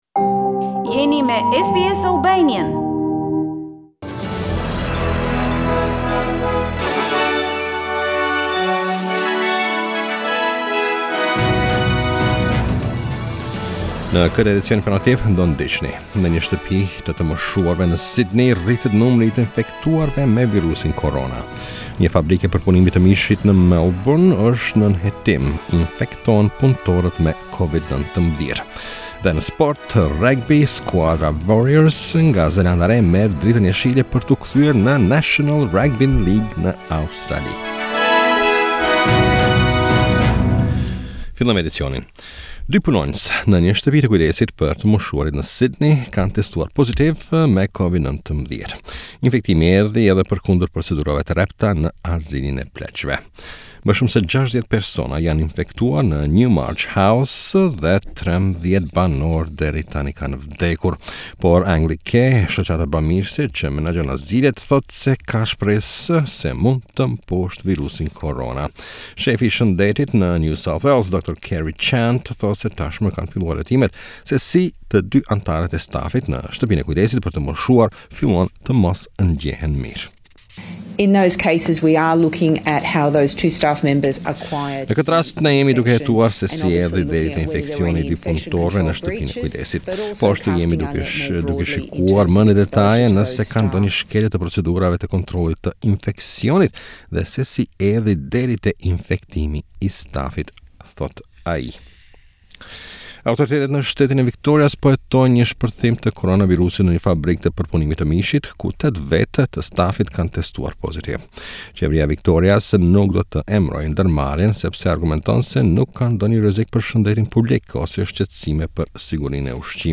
SBS News Bulletin - 2 May 2020